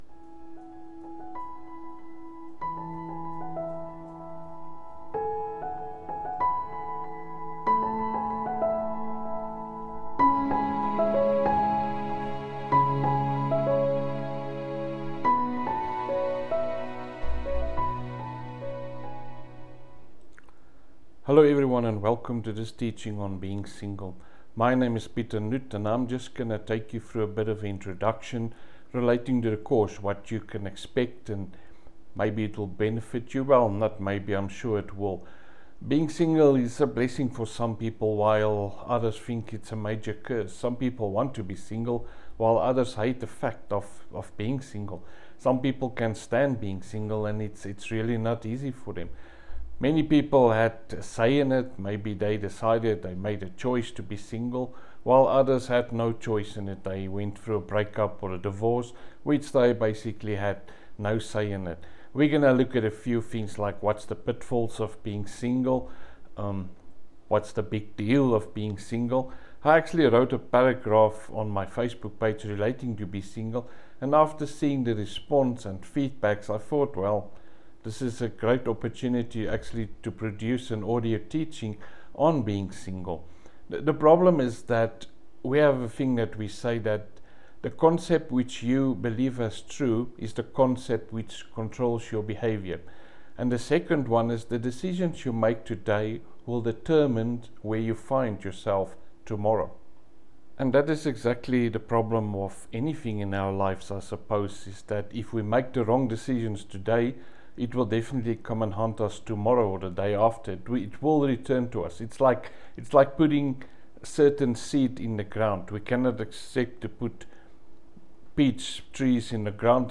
So my advice today is to take time out, get healed, grow, and never be desperate. This 50-minute audio teaching will help you tremendously to find peace in firstly being single, but also to prepare you for your next relationship.